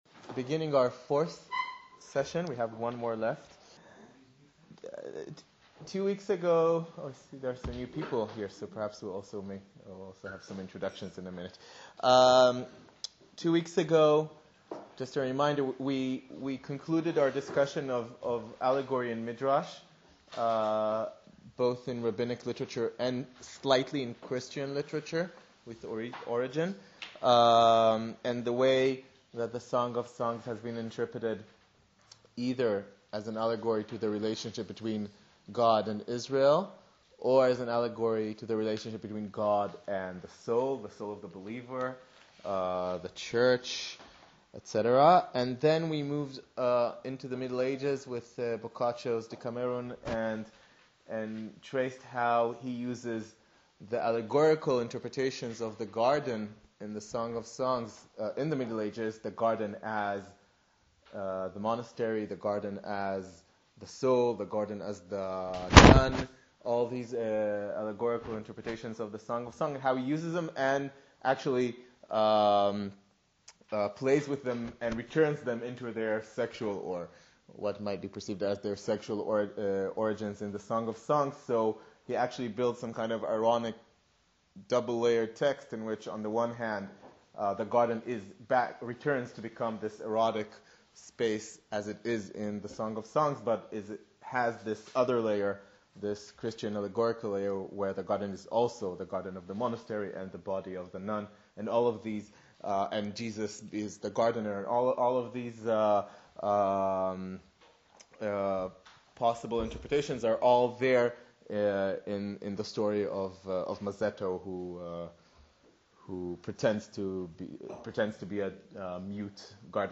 Short Presentation of Part IV lecture